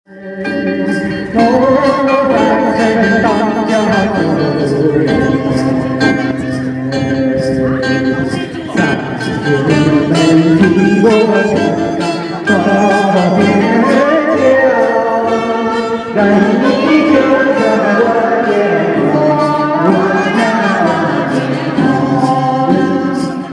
Traditional-Music.mp3